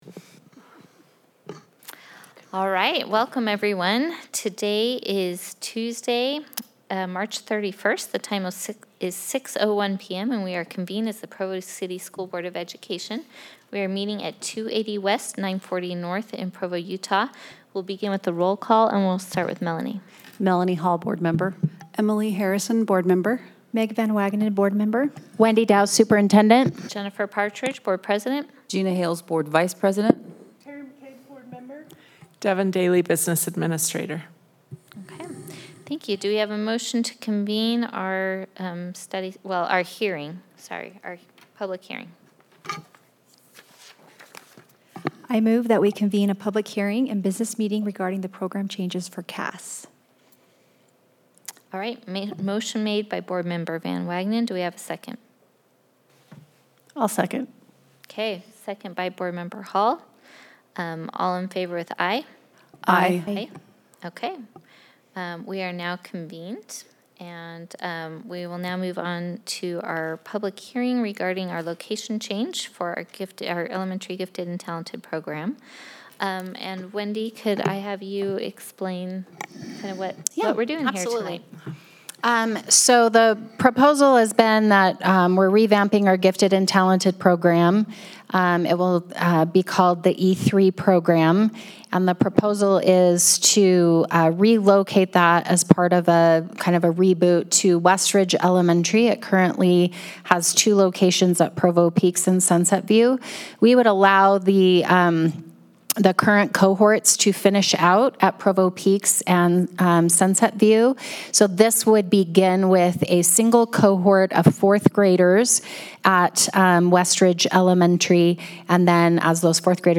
Download 2026-03-31 PCSD BOE Public Hearing.mp3 (opens in new window)